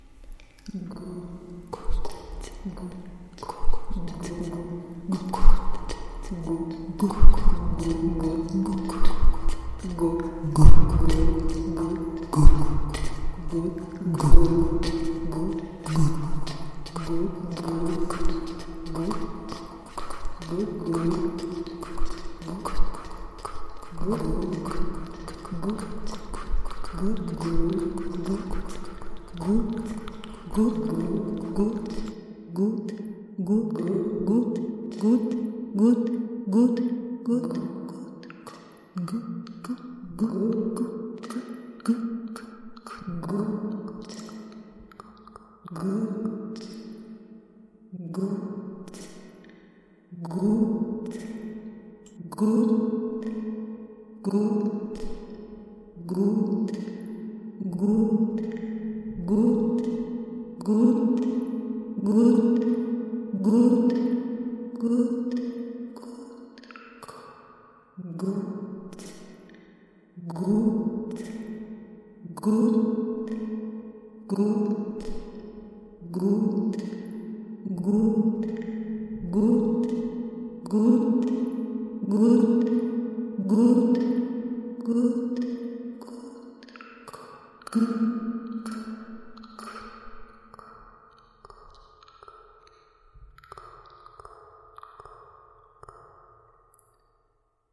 gouttesA4pistes.mp3